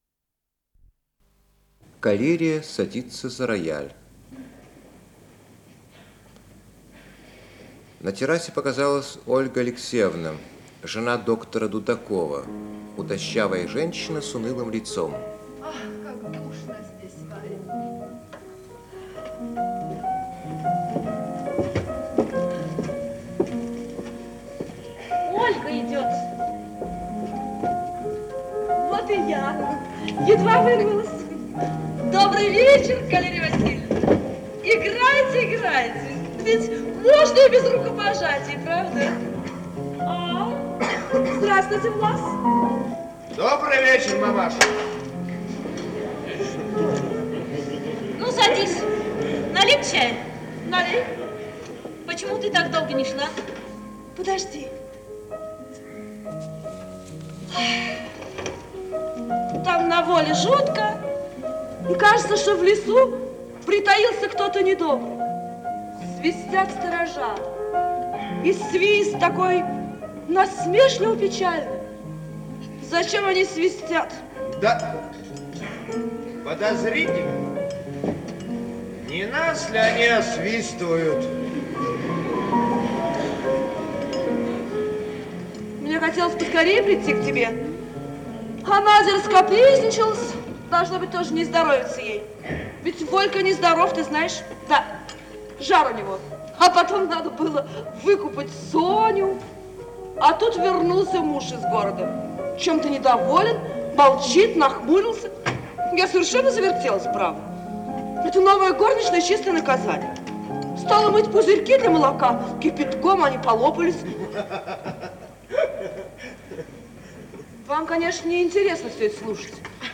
Исполнитель: Артисты театра им. Ермоловой
Запись по трансляции